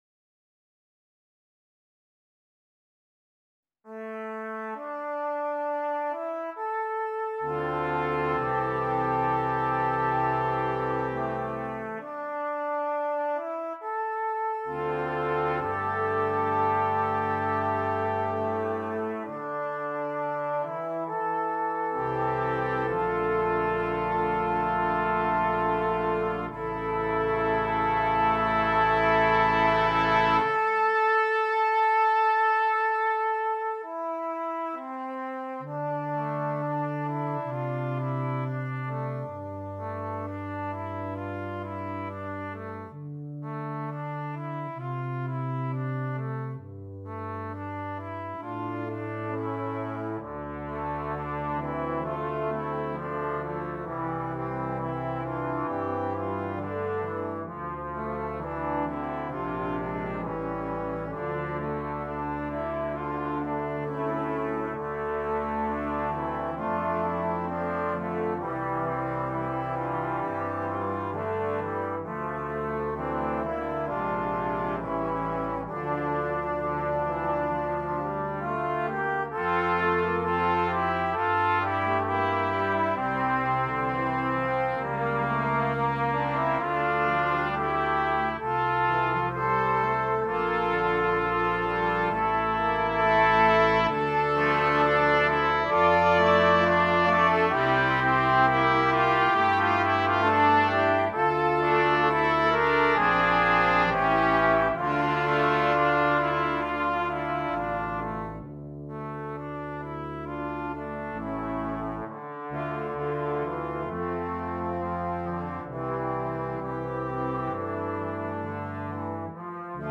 Brass Quintet
Canadian Folk Song